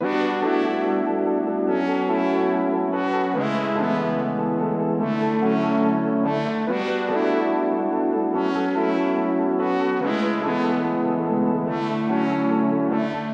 Tag: 72 bpm Pop Loops Synth Loops 2.24 MB wav Key : Unknown Ableton Live